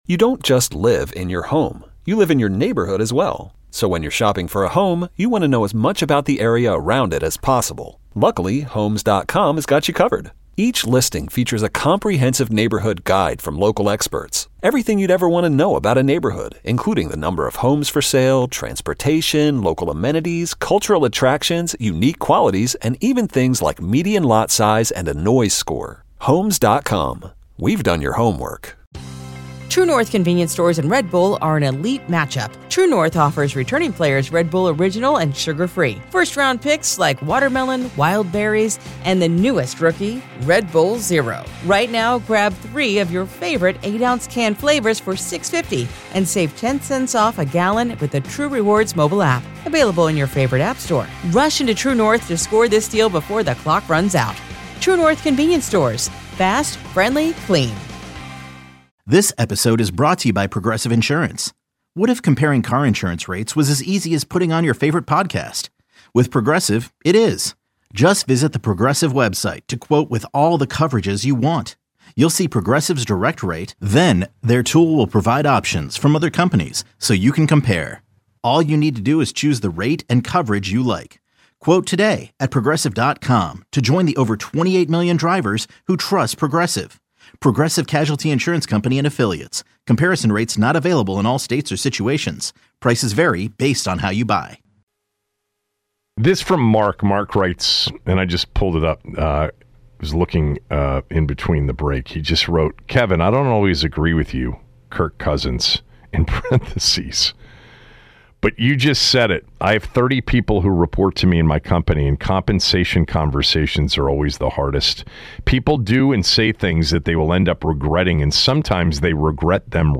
asks callers for their instant reaction to Terry McLaurin’s new contract extension.